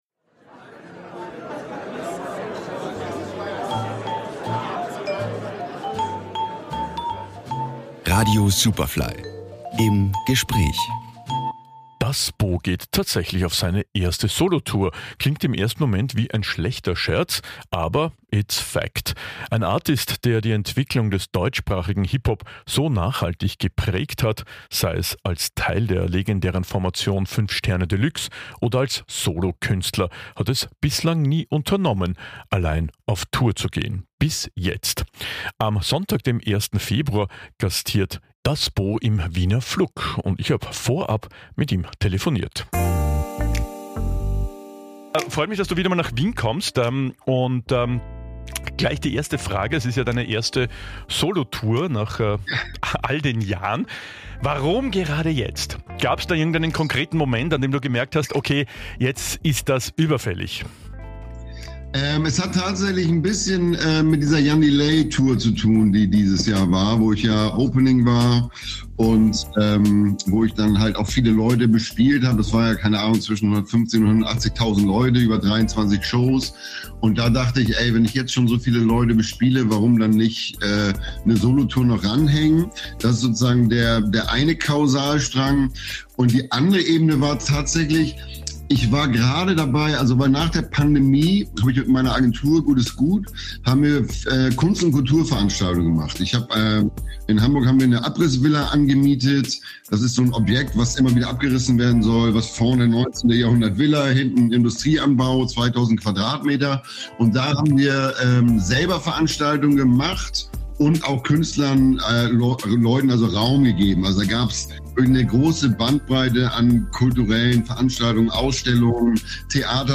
Superfly Musikgeschichten | Im Gespräch: Das Bo